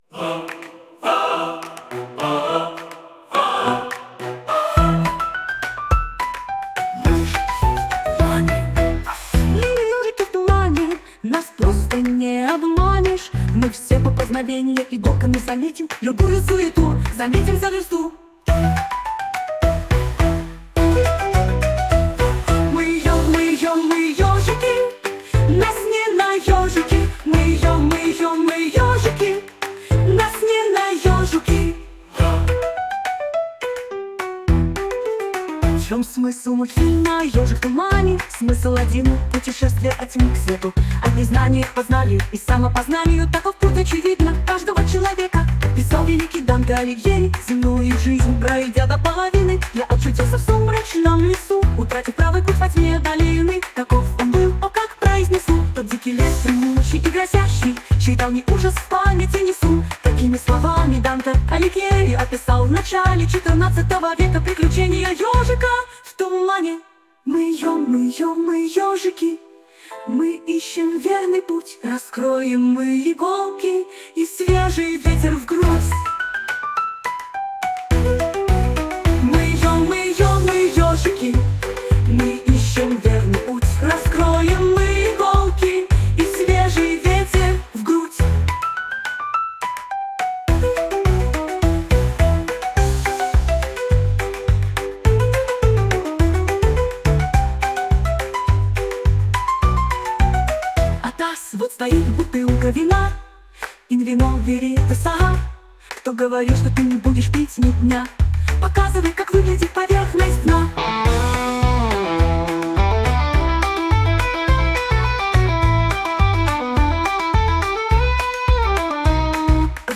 Почти детская и веселая песня в стиле мюзикла